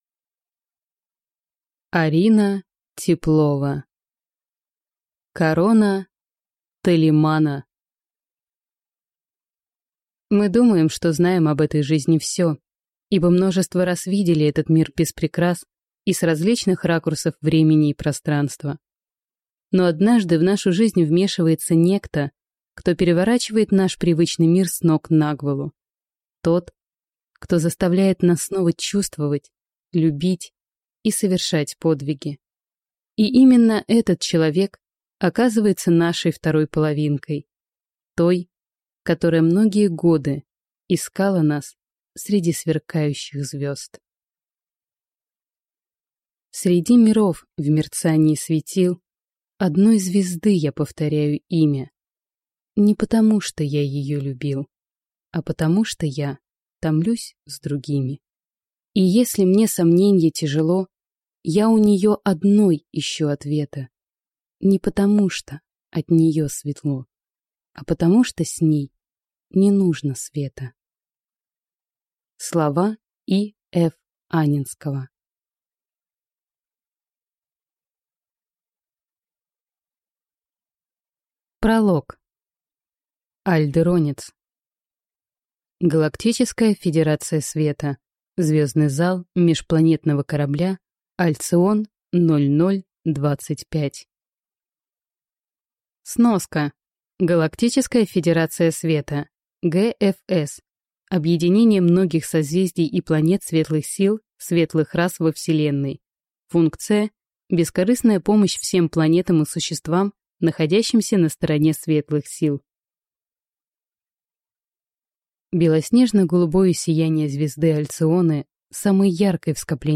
Аудиокнига Корона Толимана | Библиотека аудиокниг